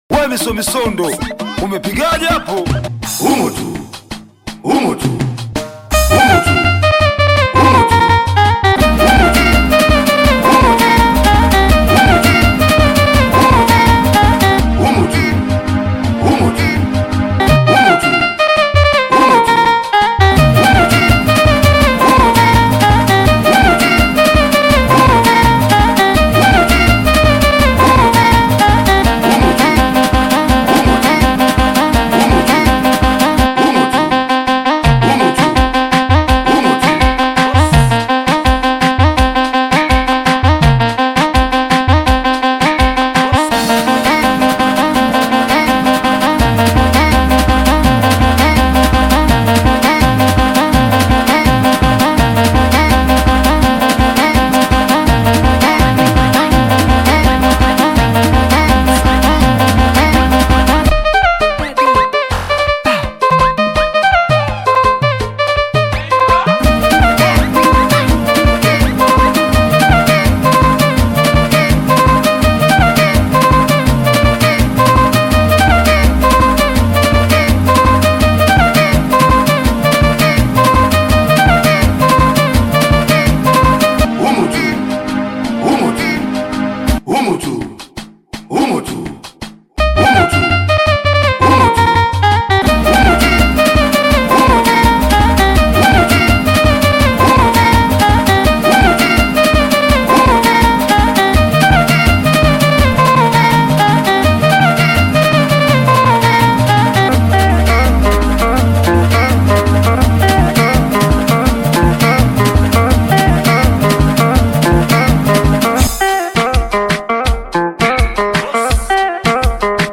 Singeli music track
Bongo Flava